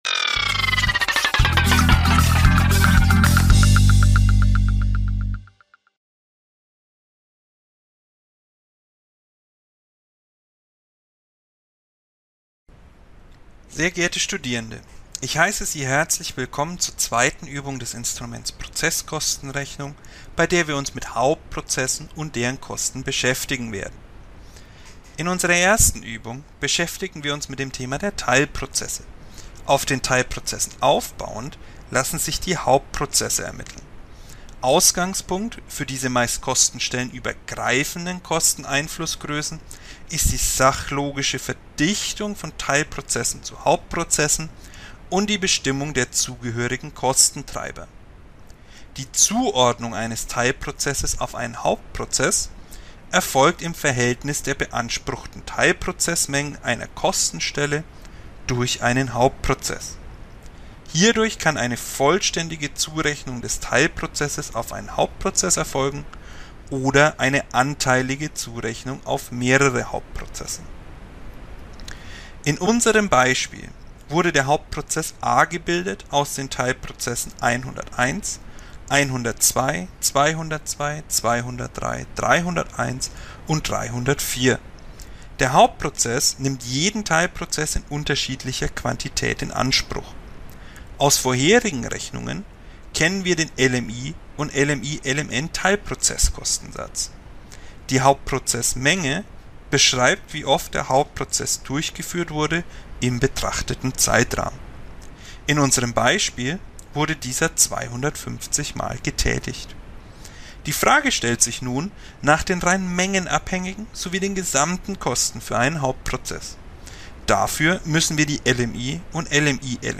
Lehrpodcast zum BWL-Studium